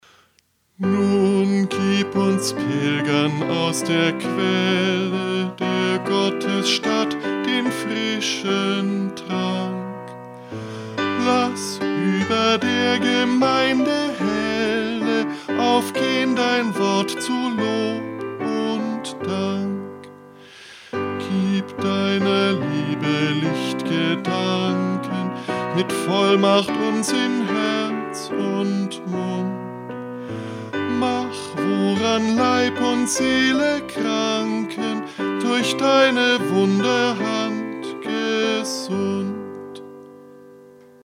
Liedtext: 1935, Otto Riethmüller (1889-1938)
Melodie: 1940, Johannes Petzold
Eingesungen: Liedvortrag von Kantor